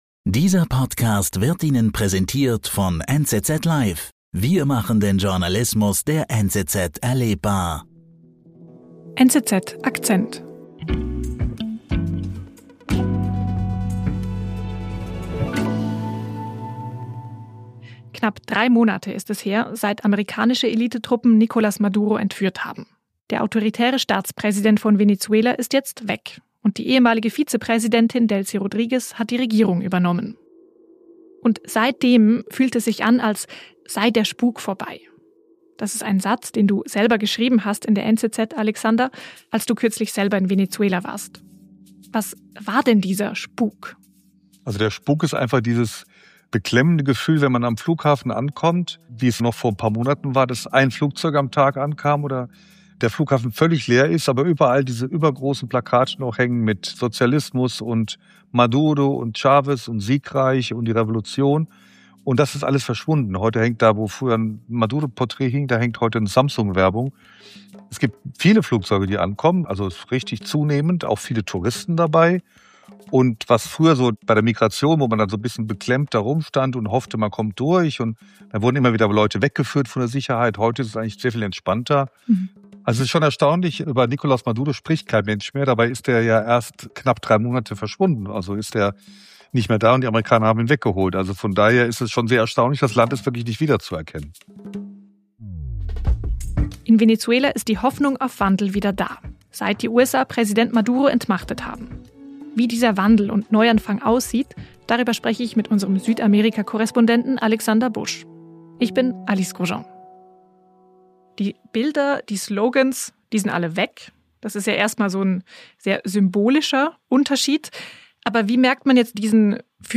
Gleichzeitig bleibt politische Unsicherheit bestehen, da Teile des alten Regimes weiterhin Einfluss haben. Im Gespräch